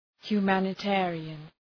Προφορά
{hju:,mænı’teərıən}